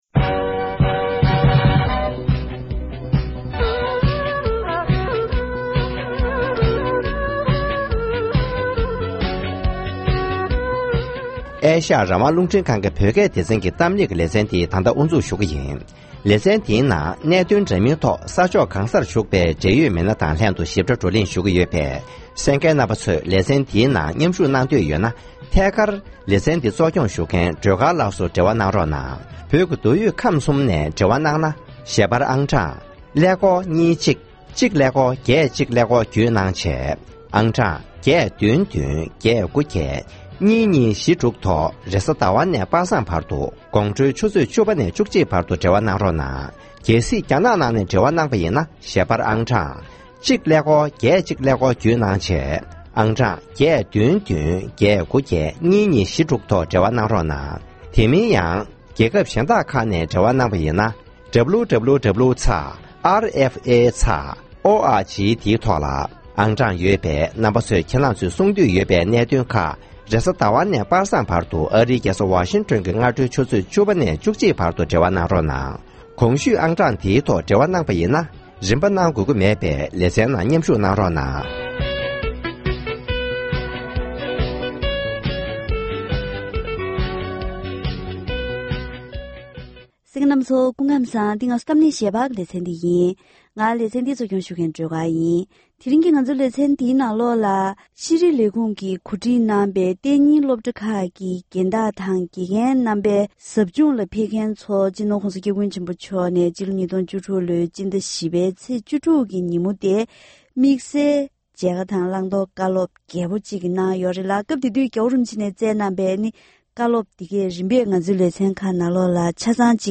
༧རྒྱལ་བའི་ཕོ་བྲང་དུ་བཙན་བྱོལ་ནང་གི་སློབ་གྲྭ་ཁག་ཅིག་གི་དགེ་རྒན་དང་རྒན་བདག་སོགས་ཚུད་པར་མཇལ་ཁ་བཀའ་སློབ་གནང་ཡོད་པ་རེད།